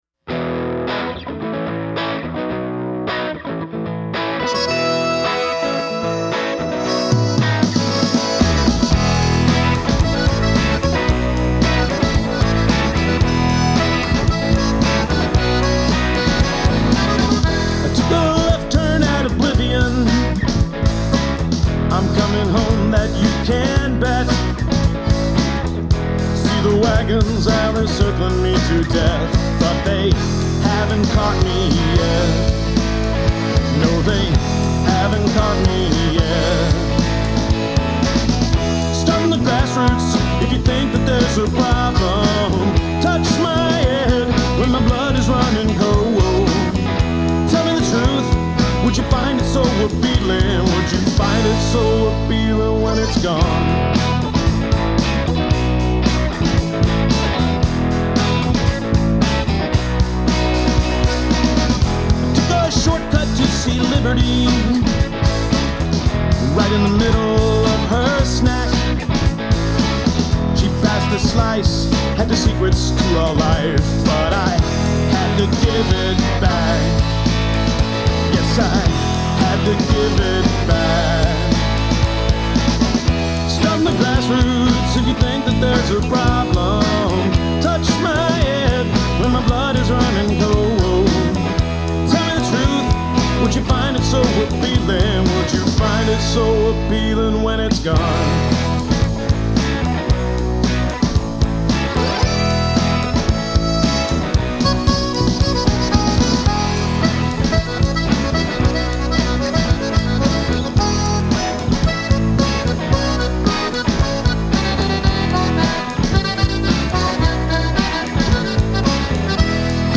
High-energy time machine that runs on pure whisky.
Americana is blues, country, jazz and rock.